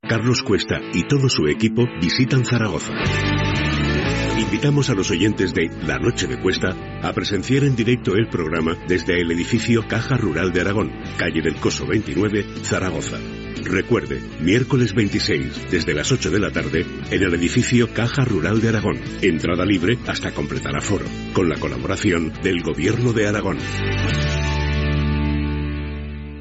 Promoció del programa especial des de Saragossa.